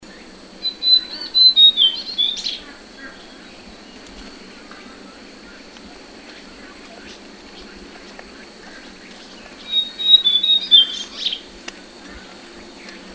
Petite Brachyptère ( Bradipteryx leucophris ) ssp carolinae
Chant enregistré le 19 juillet 2012, en Chine, province du Guangxi, à Shizi Shan près de la ville de Beishan.
Chant de Petite Brachyptère : écouter ici